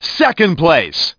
1 channel
place-2nd.mp3